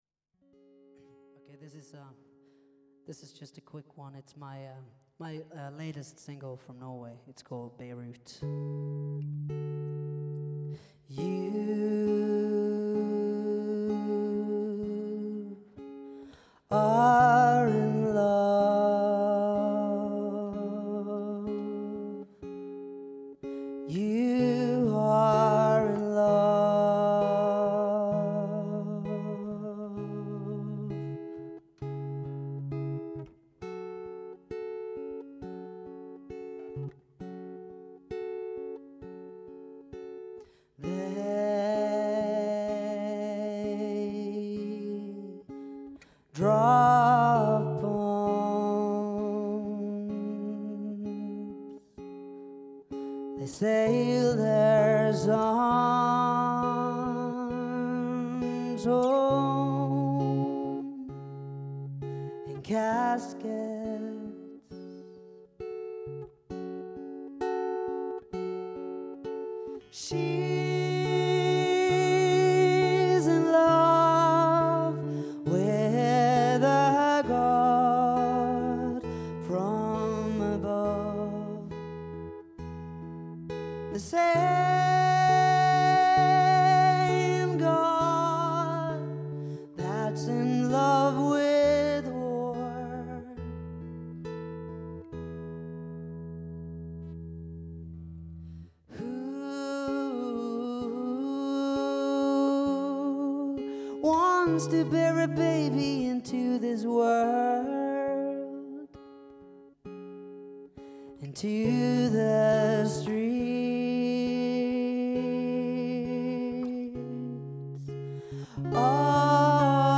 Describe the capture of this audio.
The show went down well. I recorded the whole thing.